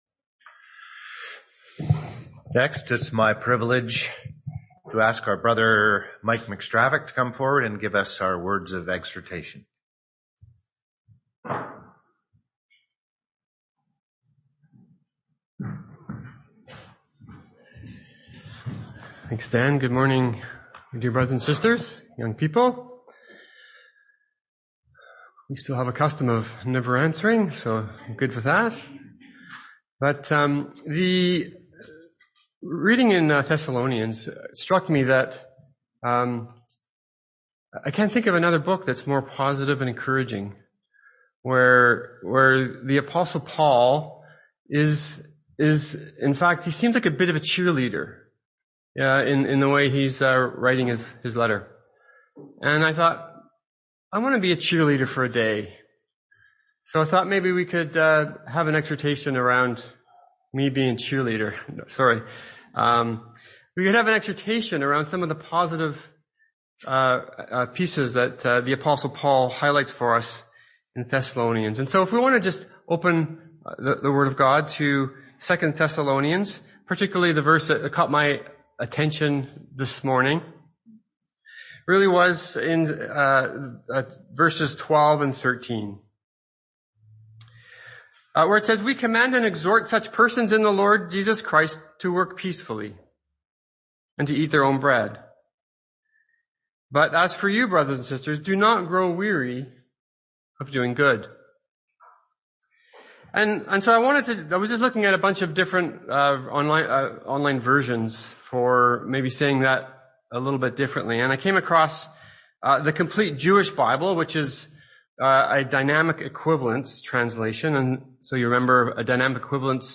Exhortation 11-20-22